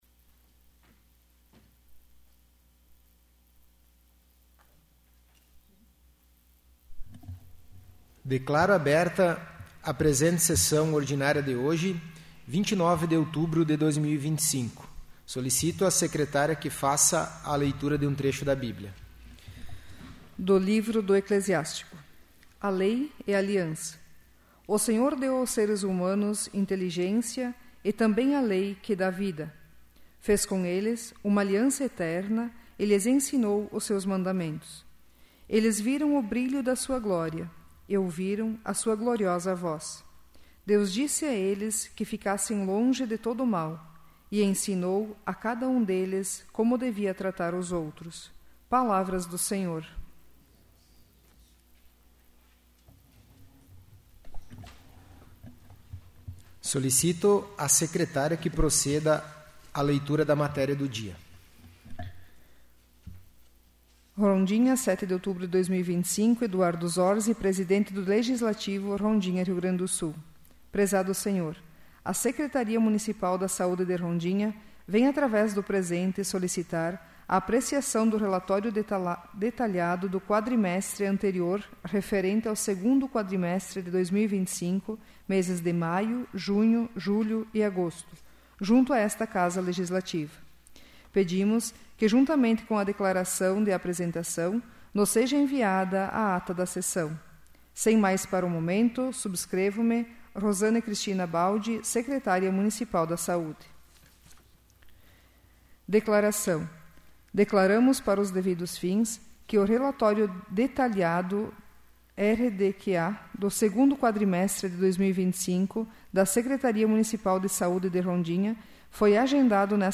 'áudio da sessão do dia 25/03/2026'